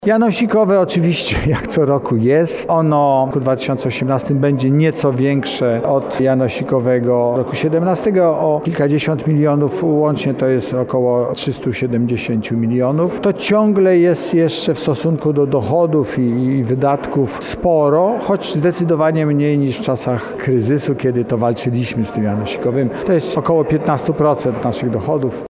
– Kolejny rok z rzędu Mazowsze musi zapłacić podatek na rzecz innych województw, tak zwane „janosikowe” – podkreśla skarbnik Mazowsza Marek Miesztalski.